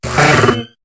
Cri de Lanturn dans Pokémon HOME.